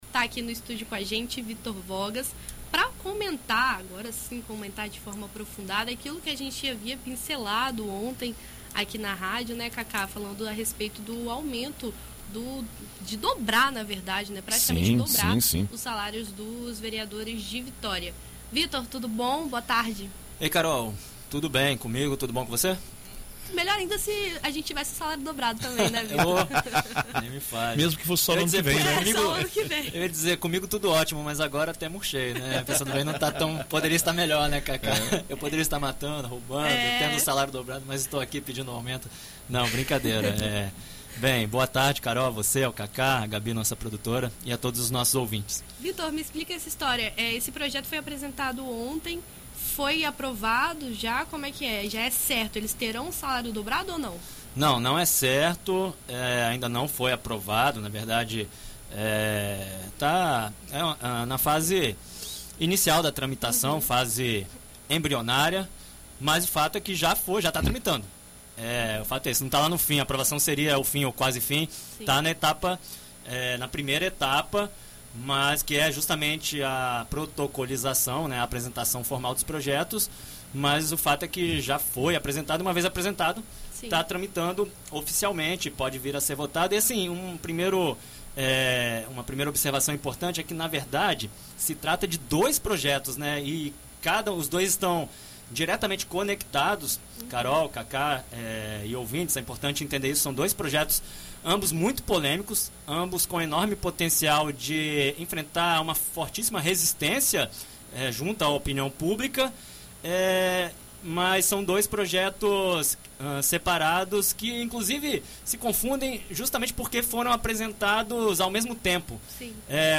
Em entrevista à BandNews FM ES nesta quinta-feira (30)